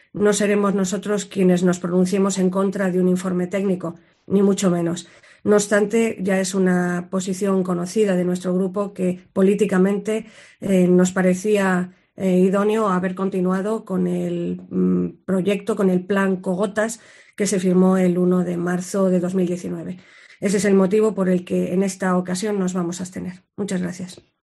Sonsoles Sánchez-Reyes, portavoz PP. Pleno noviembre. Mesa de la sequía